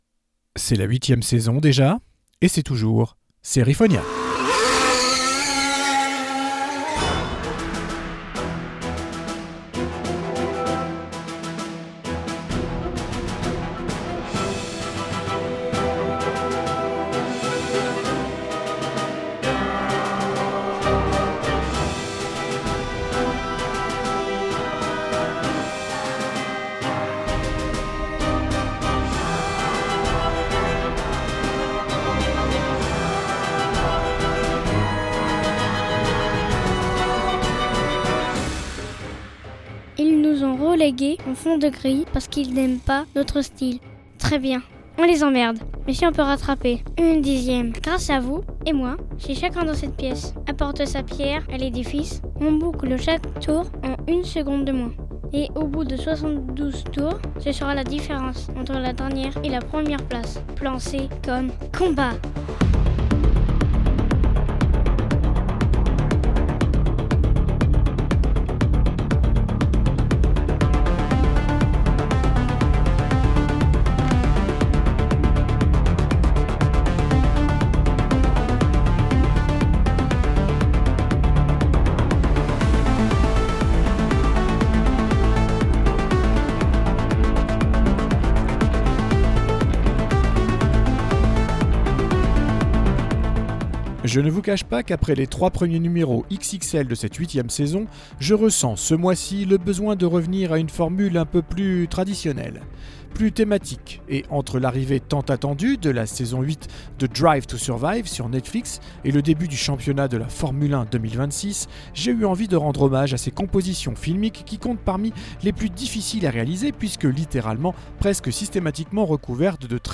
SérieFonia.[EXTRAIT : démarrage de voiture]